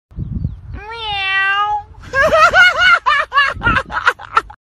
• Soft and Sweet Notification Sound
• Short and Clear Sound